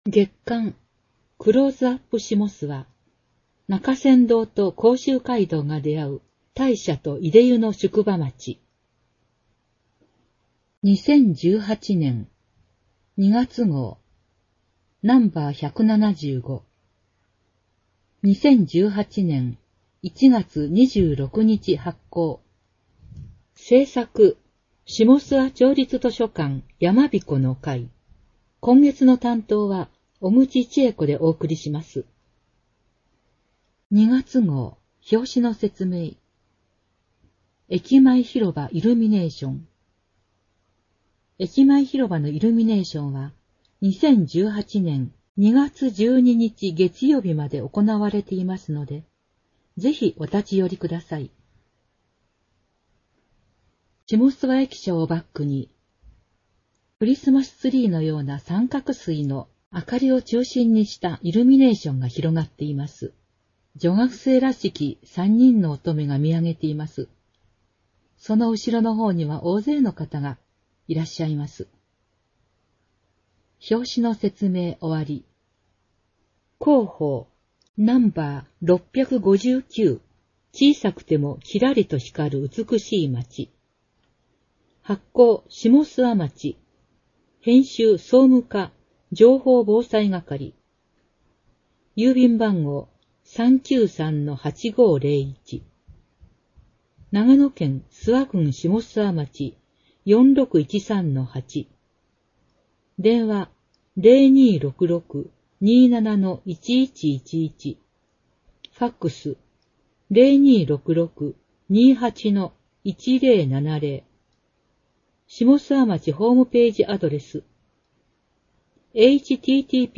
ダウンロード （音読版）クローズアップしもすわ2018年2月号 [ mp3 type：25MB ] （音読版）生涯学習５２５号 [ mp3 type：5MB ] 添付資料を見るためにはビューワソフトが必要な場合があります。